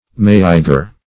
Search Result for " maiger" : Wordnet 3.0 NOUN (1) 1. large European marine food fish ; [syn: maigre , maiger , Sciaena aquila ] The Collaborative International Dictionary of English v.0.48: Maiger \Mai"ger\, n. (Zool.)